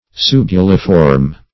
Subuliform \Su"bu*li*form\, a.